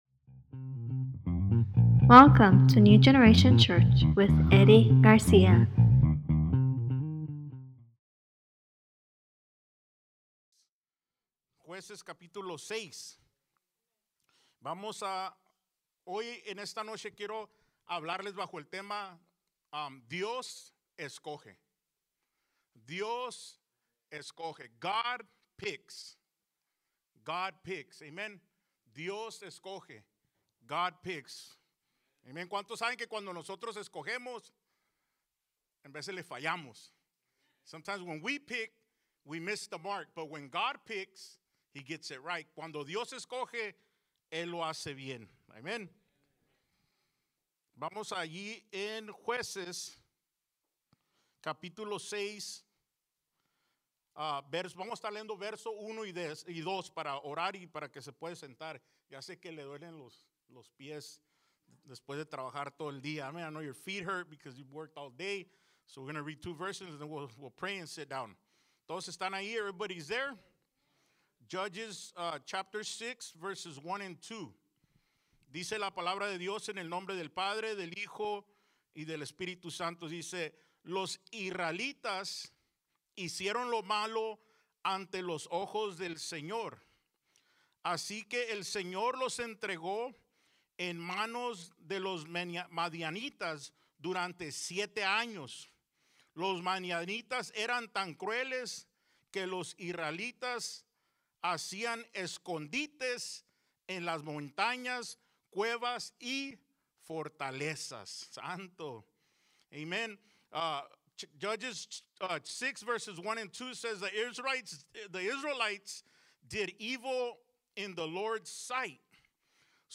ECNG Wednesday Bible Studies